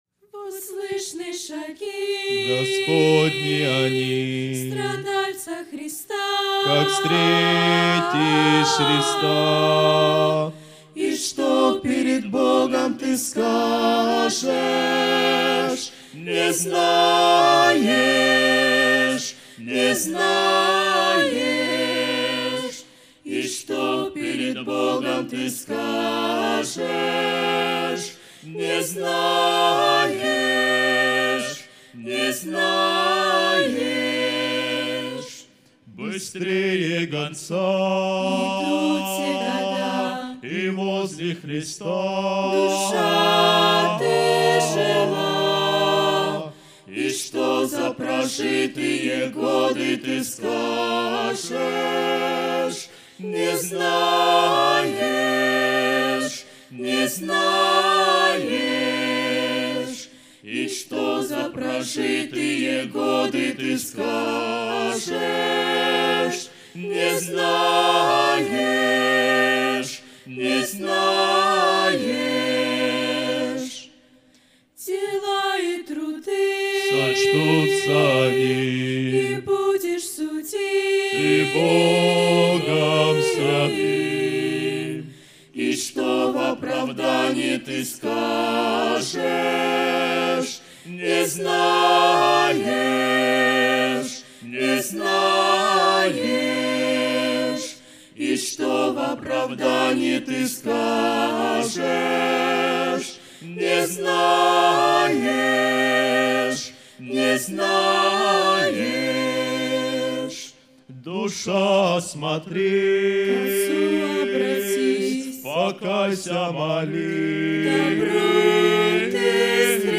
Group Song 2